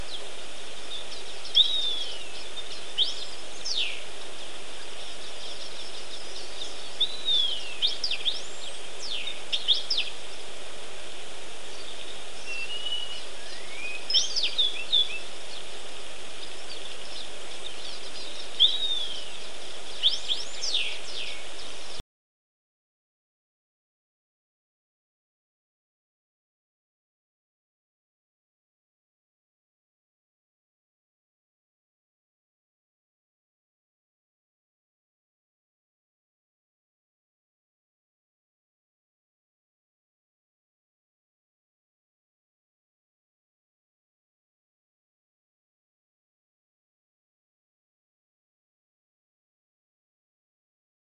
Estorninho-preto
Sturnus unicolor
Local: Cuba - Alentejo
Estorninho002.mp3